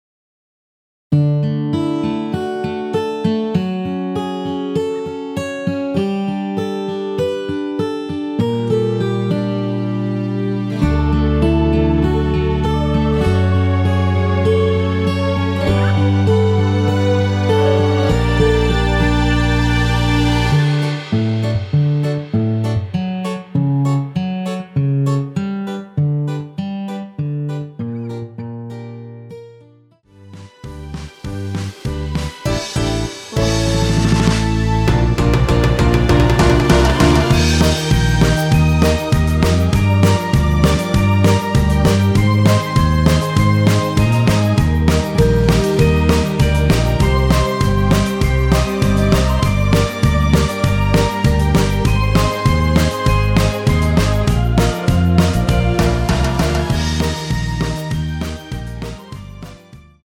원키에서(+3)올린 MR이며 여성분이 부르실수 있느키의 MR입니다.
앞부분30초, 뒷부분30초씩 편집해서 올려 드리고 있습니다.
중간에 음이 끈어지고 다시 나오는 이유는